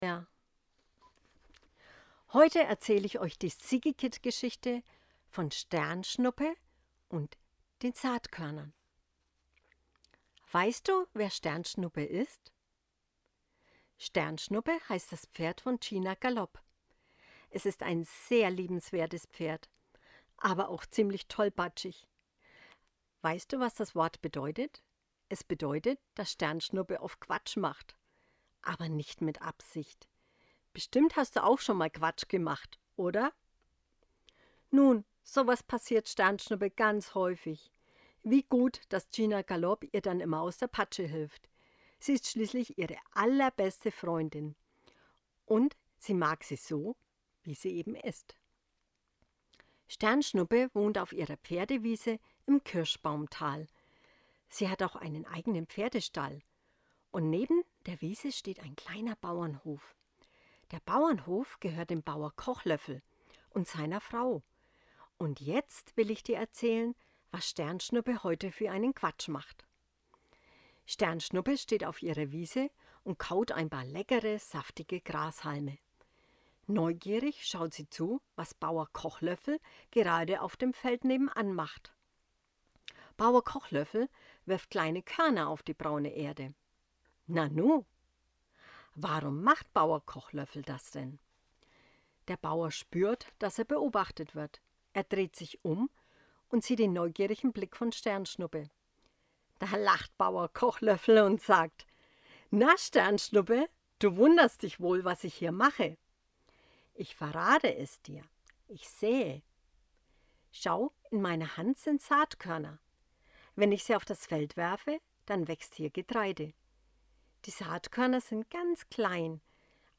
Eine Vorlesegeschichte für kleine Pferdefans. Gina´s Pferd Sternschnuppe beobachtet den Bauern beim Säen und kommt dabei auf eine äußerst amüsante Idee.